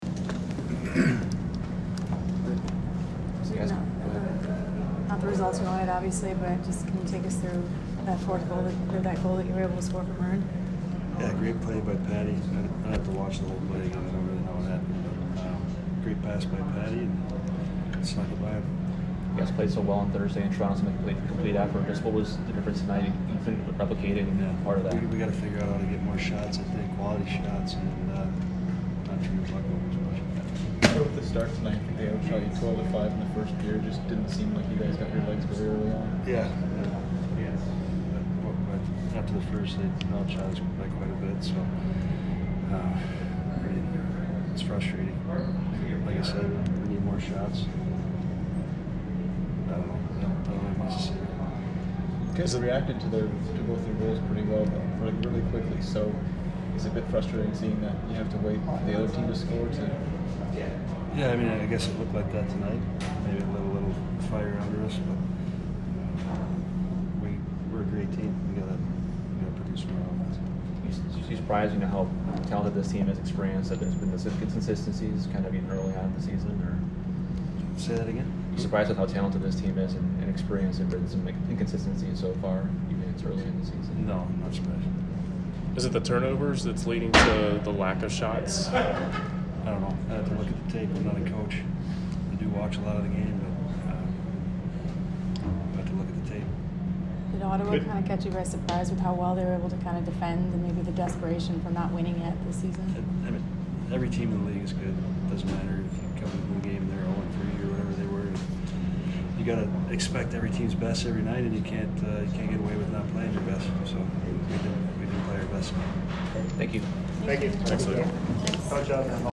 Luke Witkowski Post-Game At Ottawa Oct. 12